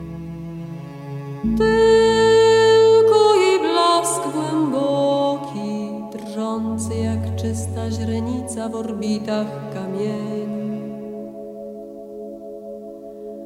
pieśniarka, instruktorka muzyki